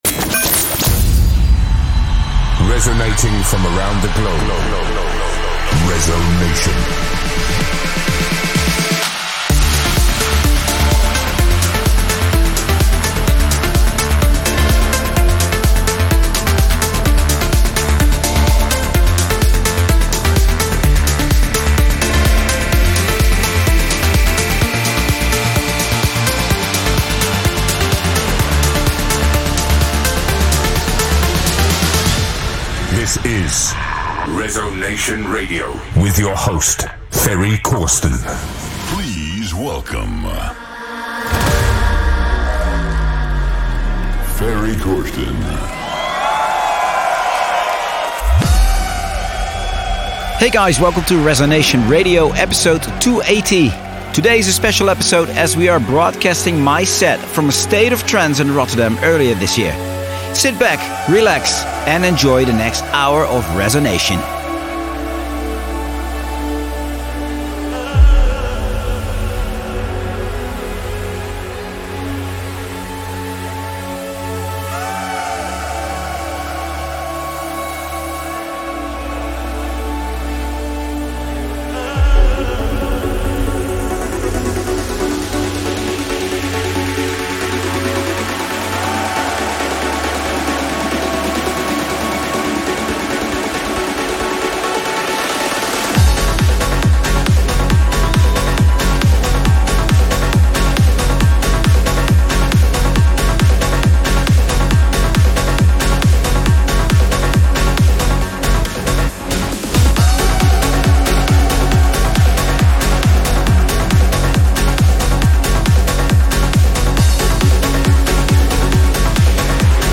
music DJ Mix in MP3 format
Genre: Trance